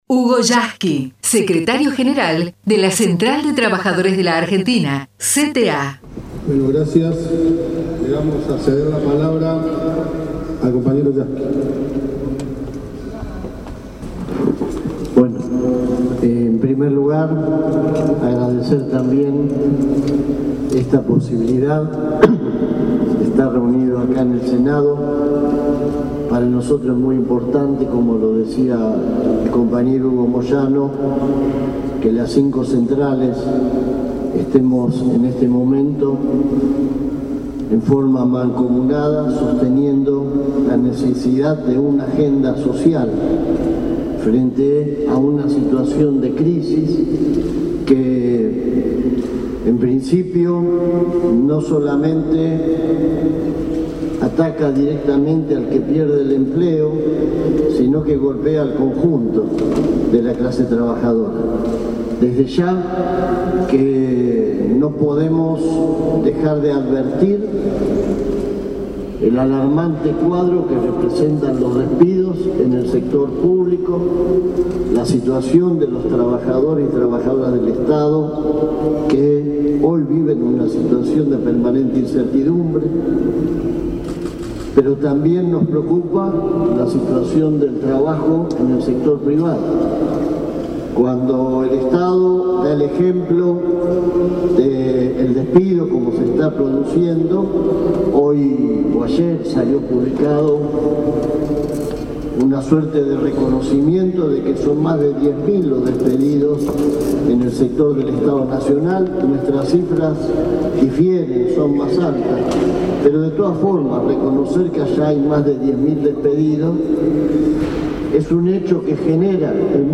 HUGO YASKY // intervención en en Senado de la Nación // SECRETARIO GENERAL CTA
final-hugo_yasky_en_el_senado_-.mp3